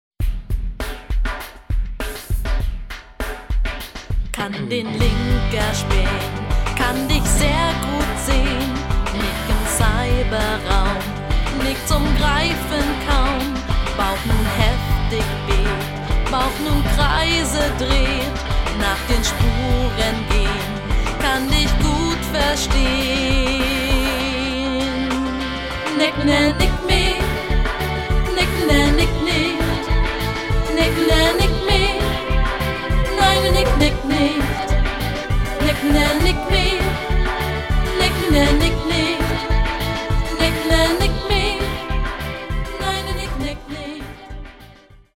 Genre: Rockpop
Qualität: MP3, Stereo